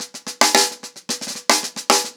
TheQuest-110BPM.15.wav